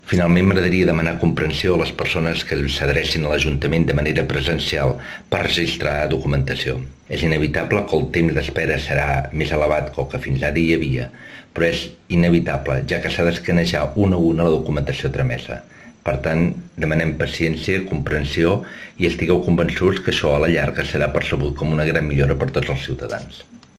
El regidor de Comunicació, transparència i participació Josep Maria Castells ho explica a Ràdio Capital